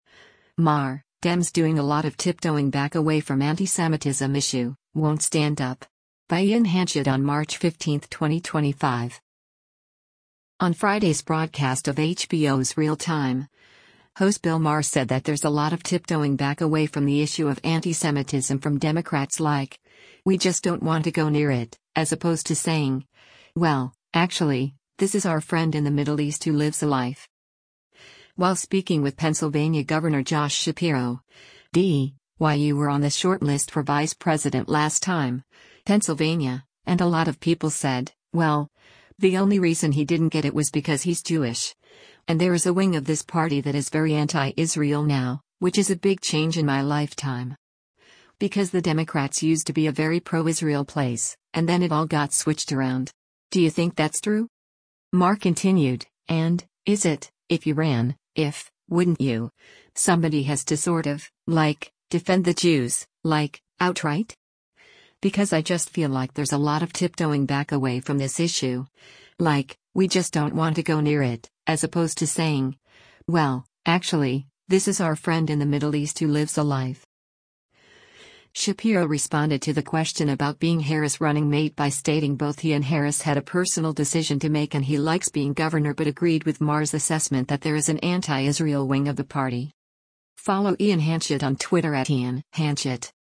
On Friday’s broadcast of HBO’s “Real Time,” host Bill Maher said that “there’s a lot of tiptoeing back away” from the issue of antisemitism from Democrats “like, we just don’t want to go near it, as opposed to saying, well, actually, this is our friend in the Middle East who lives a life.”